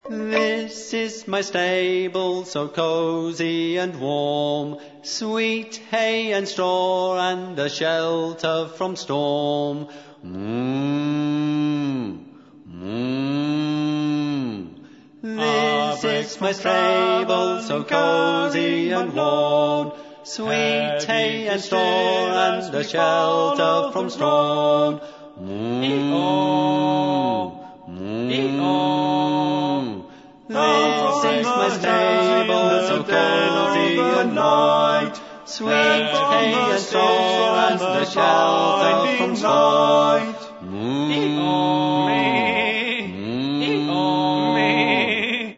First part, 0:45 sec, mono, 22 Khz, file size: 175 Kb.